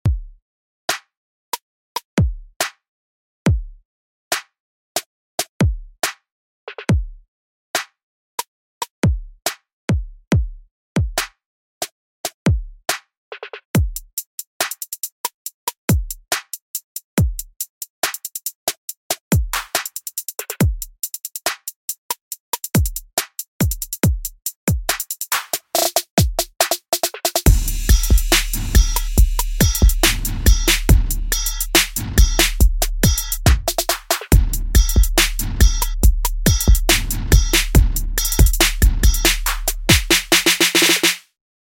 Trap Beat [ 70 Bpm ]